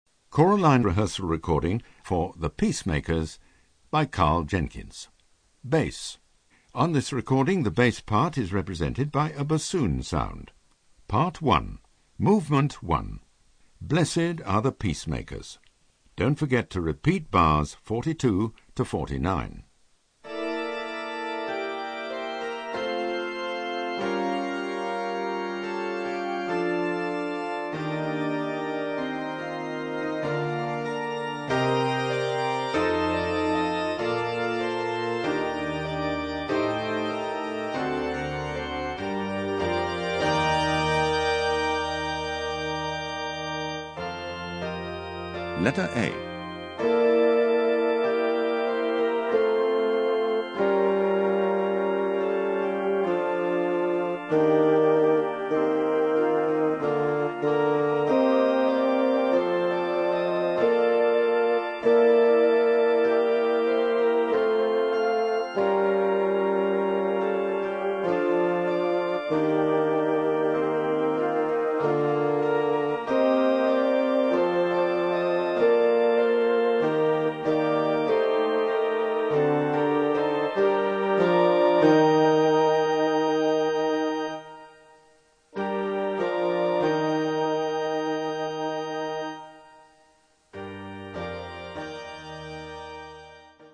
Bass
Easy To Use narrator calls out when to sing
Don't Get Lost narrator calls out bar numbers
Vocal Entry pitch cue for when you come in